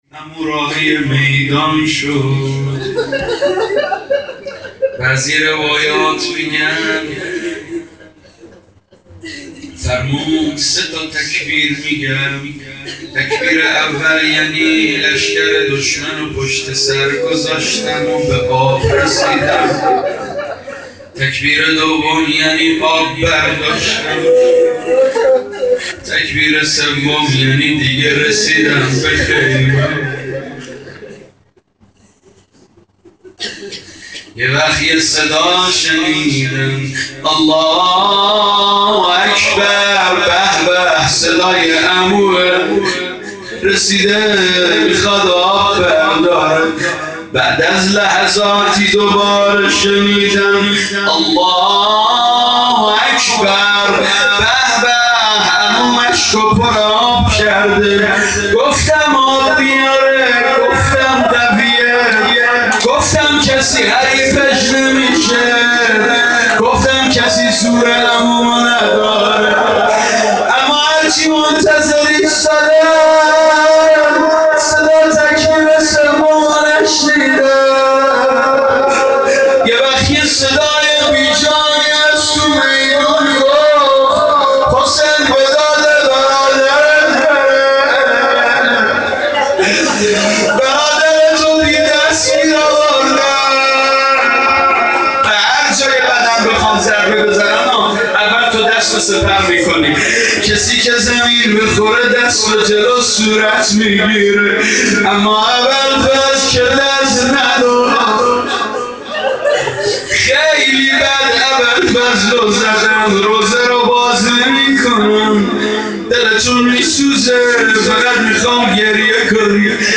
مناجات خوانی با نوای گرم